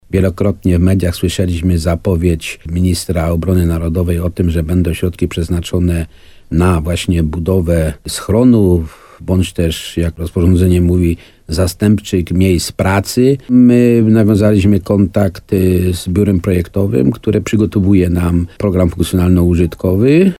– W razie wystąpienia niebezpieczeństwa, możliwe będzie szybkie przeniesienie się do podziemia – mówi wójt Nawojowej Stanisław Kiełbasa.